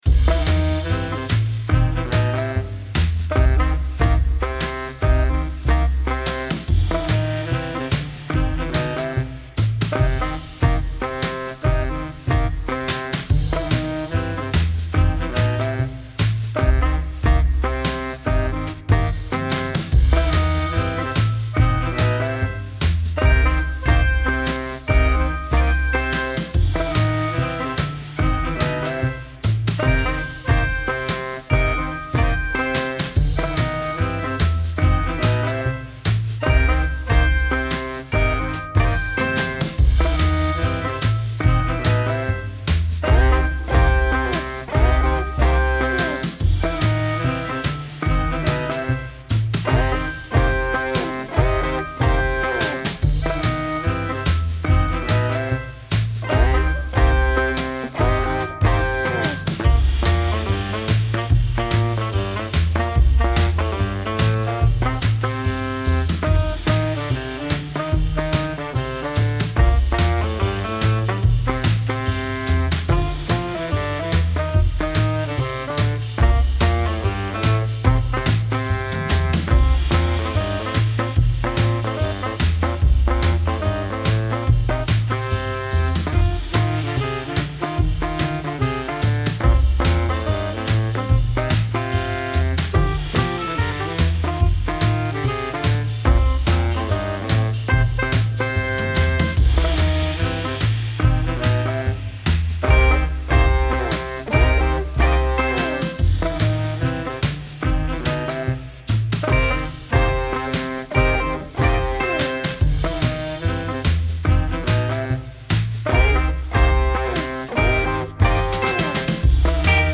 Style Style Jazz, Oldies, Rock
Mood Mood Bright, Cool, Relaxed
Featured Featured Bass, Brass, Clarinet +3 more
BPM BPM 145
It's proper generic "vintage" music.